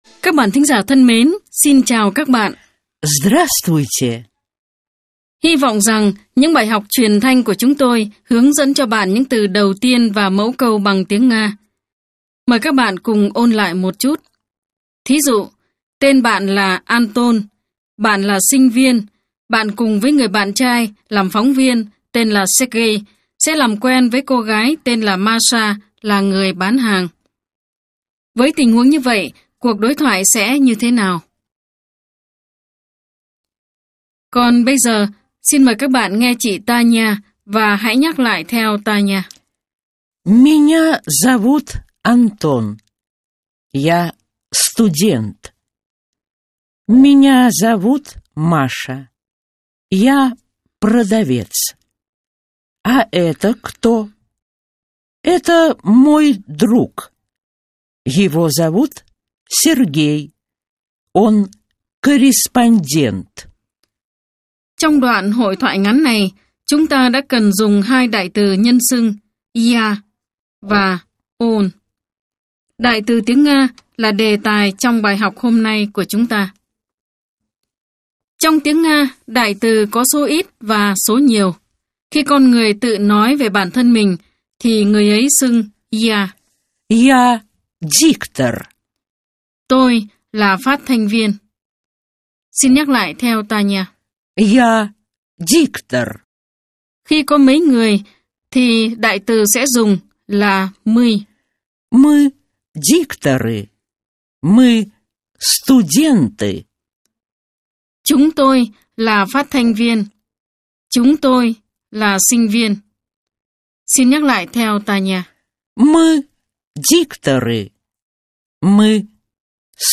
Bài 3 – Bài giảng tiếng Nga
Nguồn: Chuyên mục “Chúng ta học tiếng Nga” đài phát thanh  Sputnik